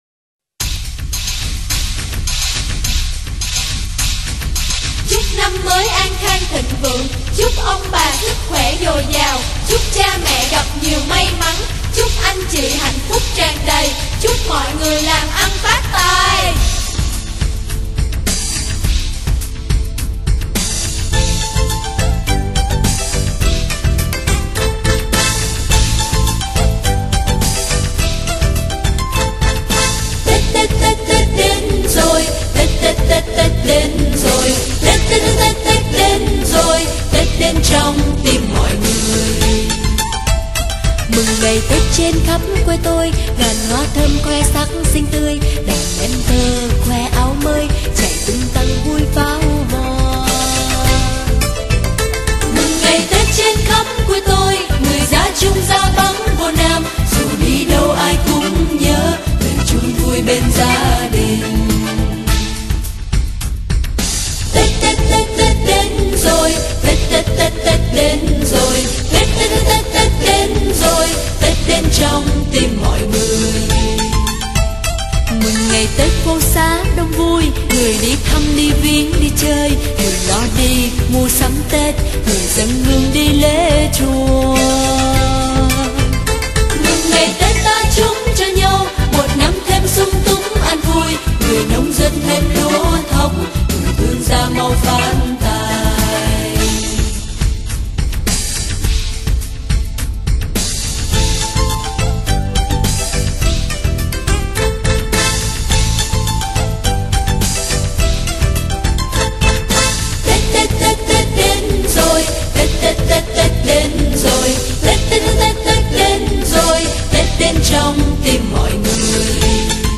Sách nói | Tết Tết Tết Tết Đến Rồi